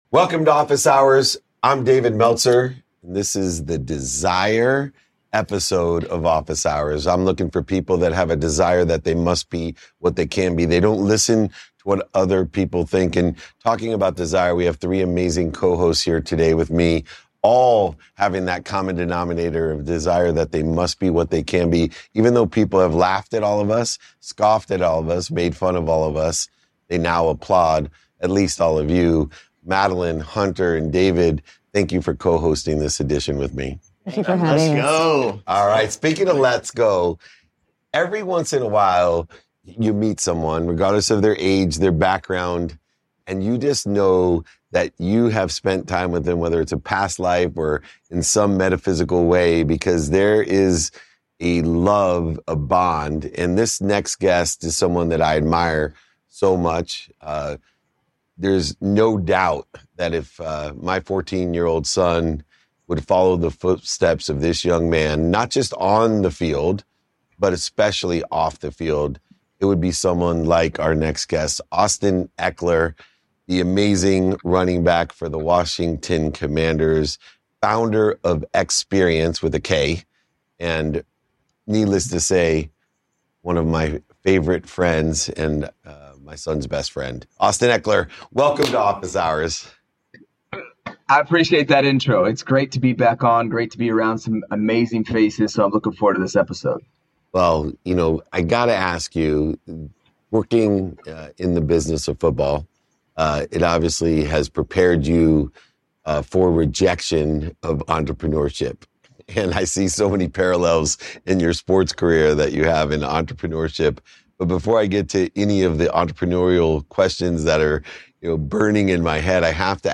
In today’s episode, I sit down with Austin Ekeler, running back for the Washington Commanders and founder of Eksperience, a platform that connects fans with athletes in personalized ways. We discuss how Austin’s journey from an underdog to an NFL leader mirrors his entrepreneurial path, emphasizing the importance of resilience and community. Austin shares his approach to leadership, both on the field and in his business, and reflects on how small, consistent actions compound into significant achievements.